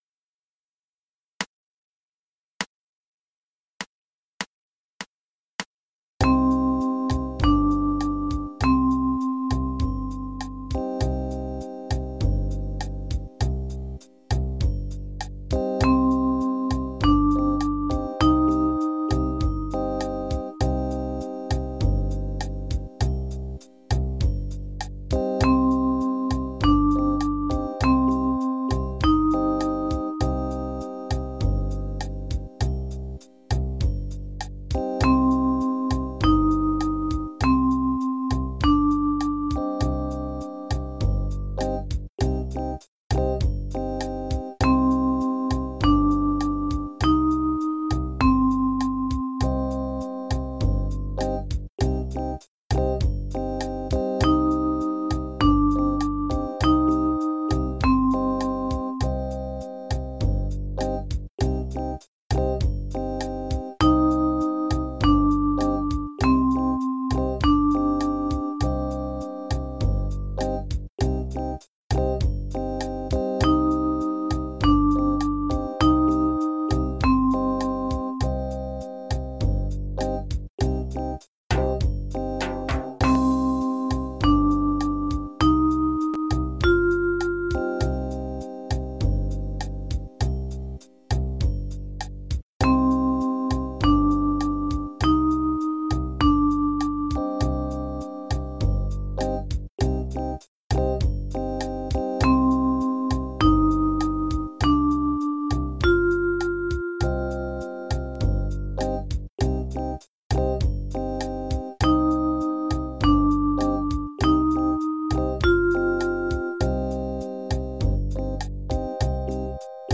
The notes used in these exercises are the first five notes of the minor scale. For the key of C major, these notes are C, D, Eb, F and G. In each of the exercises, a two-measure pattern of notes is played. Listen to the pattern, then play it in the next two measures.